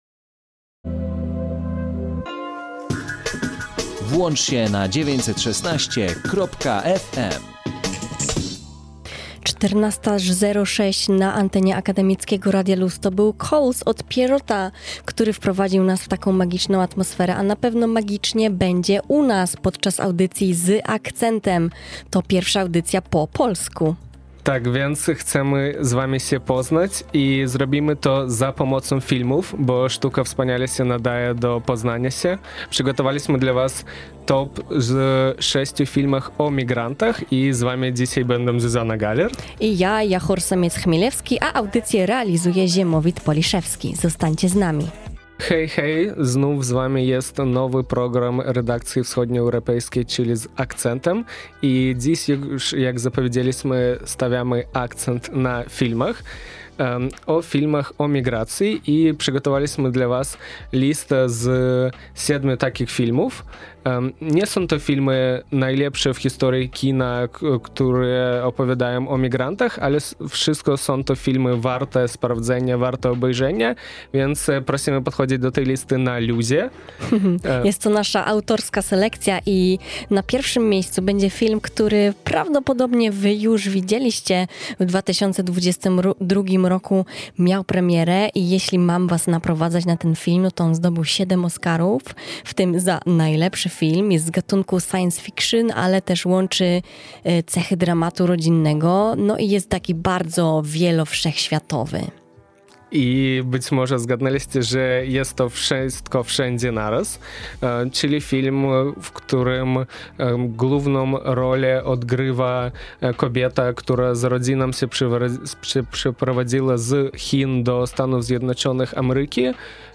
W dzisiejszym materiale usłyszycie również sondę z opiniami widzów, zebranymi po seansie tego filmu we wrocławskim Kinie Nowe Horyzonty .